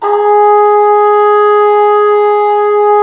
Index of /90_sSampleCDs/Roland L-CDX-03 Disk 2/BRS_Cup Mute Tpt/BRS_Cup Ambient